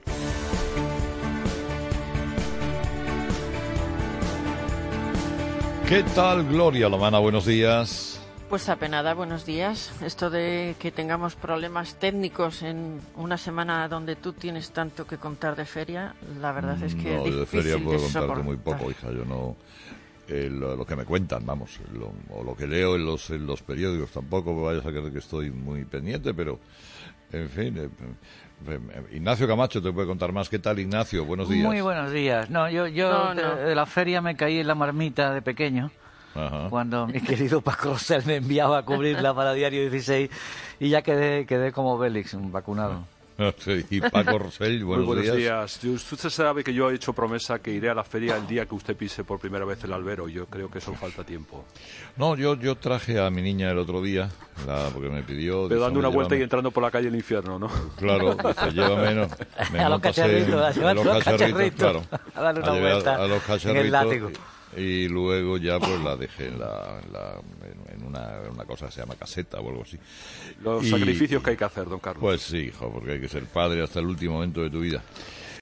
El comunicador explica la laboriosa "Purga de Benito" que obliga a reducir las emisiones desde COPE Sevilla